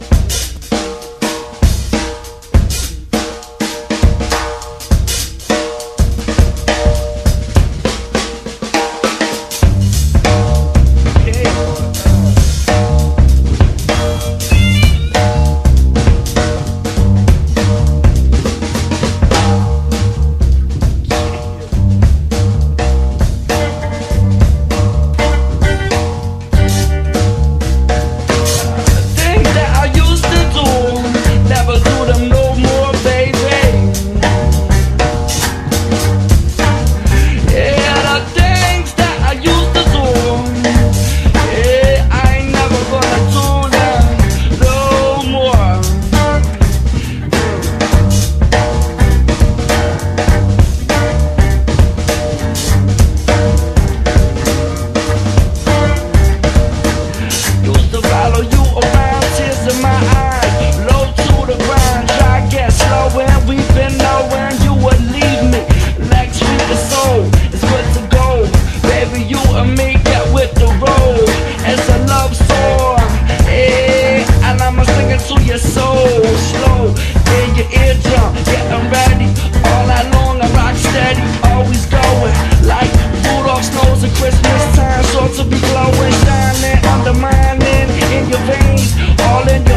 ROCK / S.S.W./A.O.R.
メロウにこみ上げる旋律に、間奏の乾いたギター・ソロも泣かせる
ザクザクしたギターにメロウなエレピ、ブルースハープも沁みるファンキーなスワンプ調